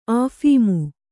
♪ āphīmu